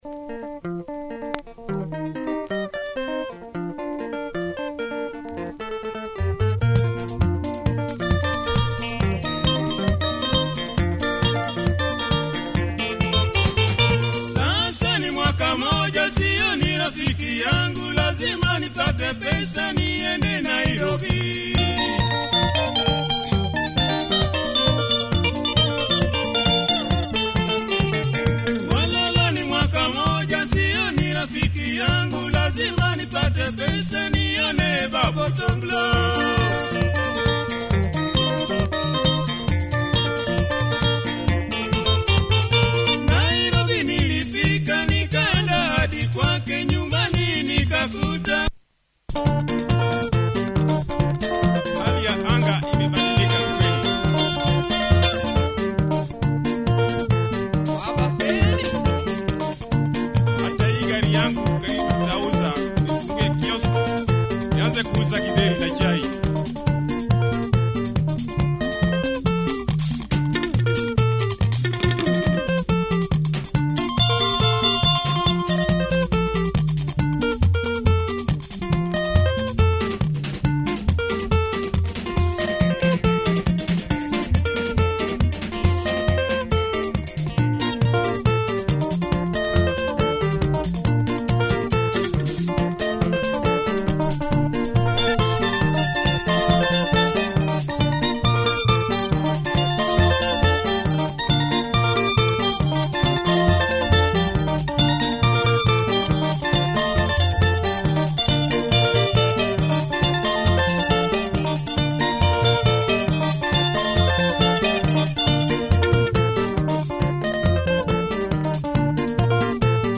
Lovely KAMBA benga by this great group